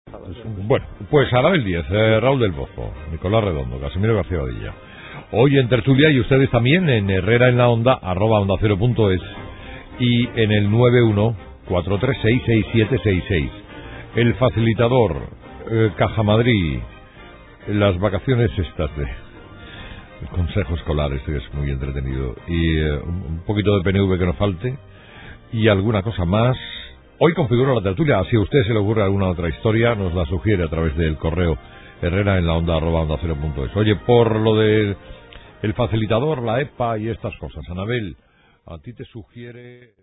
Como ejemplo de esta corriente de crítica cínica, tan de moda, el ingenioso divo de las ondas Carlos Herrera nos obsequia con un derroche de su má genuino estilo, ácido, divertido, autocomplaciente e, informativamente, inútil.